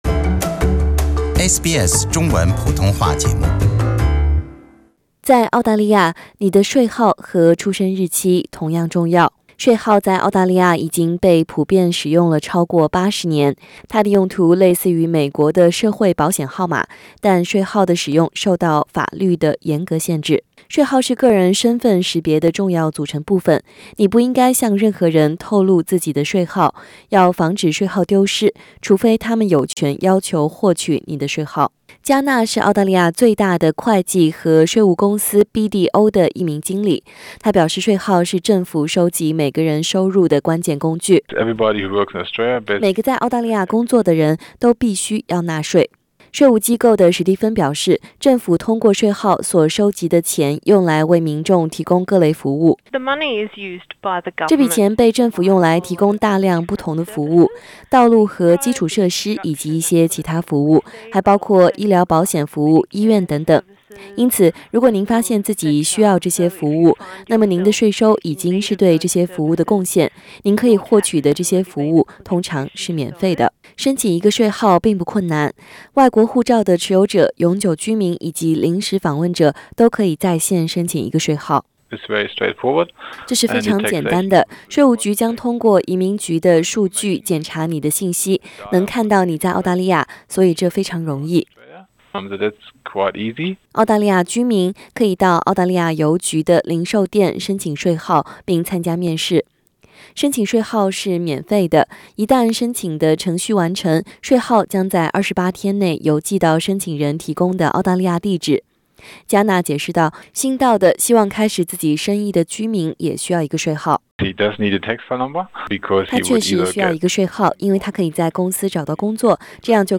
05:29 Source: AAP SBS 普通话电台 View Podcast Series Follow and Subscribe Apple Podcasts YouTube Spotify Download (10.05MB) Download the SBS Audio app Available on iOS and Android 对新移民来说，在澳大利亚开始工作之前，有几件重要的事情需要考虑。